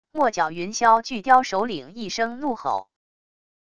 墨角云霄巨雕首领一声怒吼wav音频